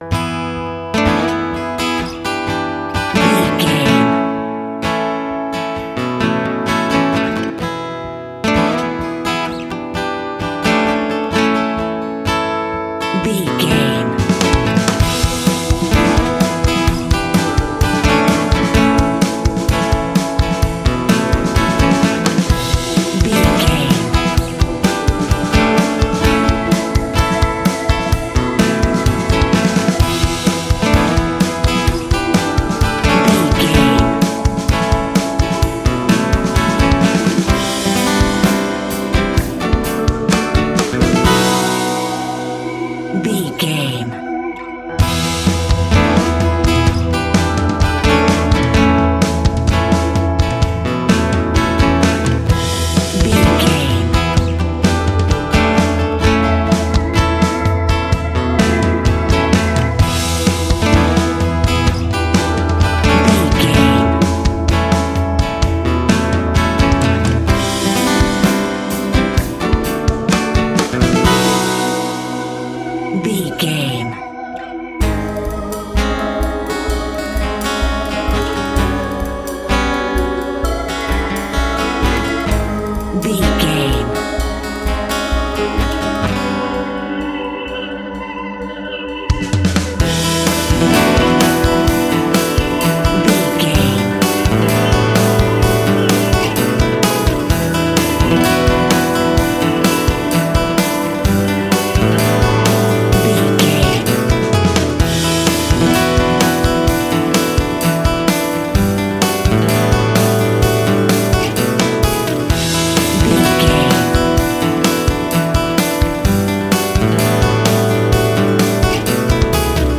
Ionian/Major
romantic
happy
acoustic guitar
bass guitar
drums